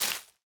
Minecraft Version Minecraft Version 1.21.5 Latest Release | Latest Snapshot 1.21.5 / assets / minecraft / sounds / block / leaf_litter / break1.ogg Compare With Compare With Latest Release | Latest Snapshot